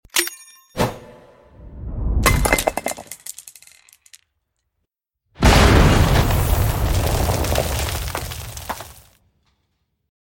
Hand Greande Sound FX (NO Sound Effects Free Download